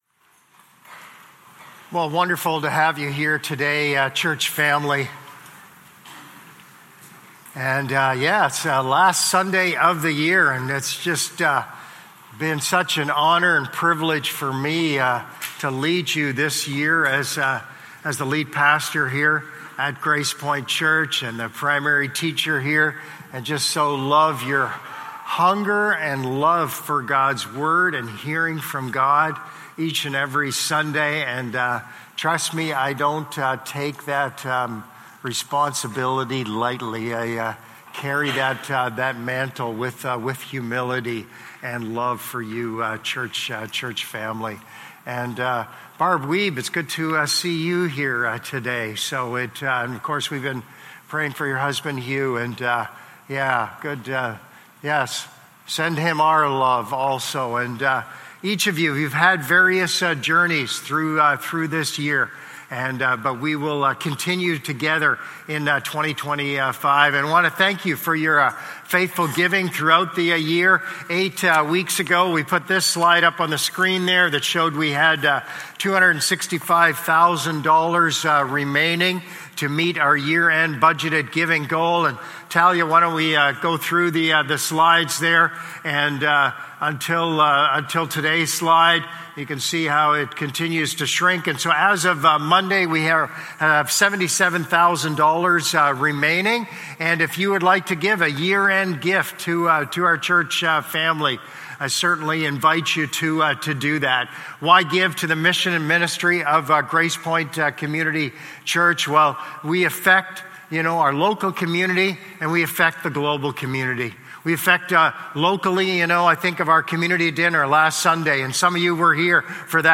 Sermons | Gracepoint Community Church